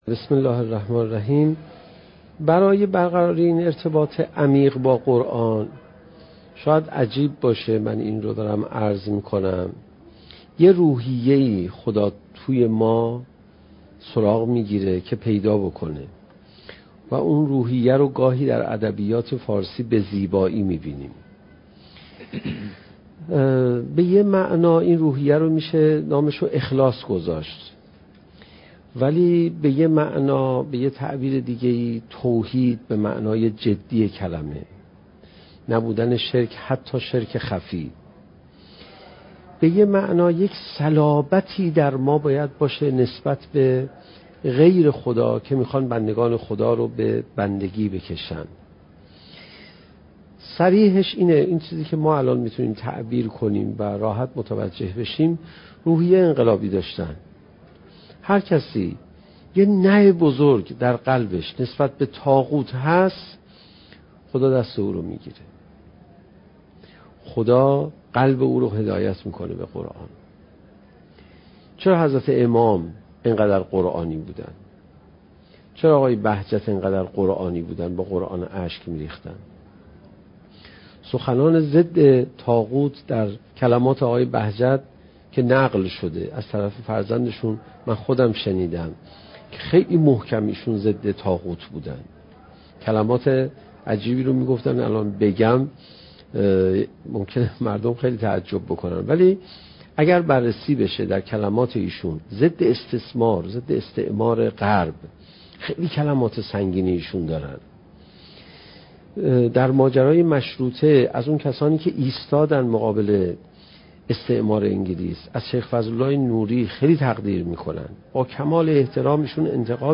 سخنرانی حجت الاسلام علیرضا پناهیان با موضوع "چگونه بهتر قرآن بخوانیم؟"؛ جلسه بیست و یکم: "برخورد خردمندانه با قرآن"